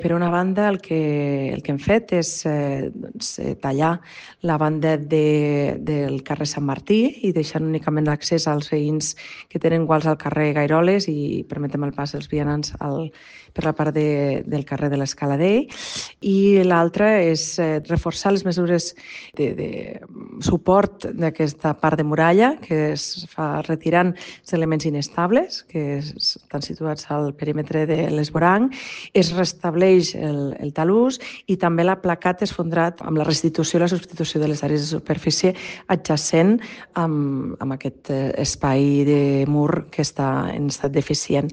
Ficheros relacionados Tall de veu de la regidora Pilar Bosch sobre l'actuació d’urgència per part de la Paeria per estabilitzar el tram del Baluard de la Llengua de Serp afectat per un esfondrament.